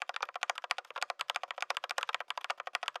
SFX_Typing_01.wav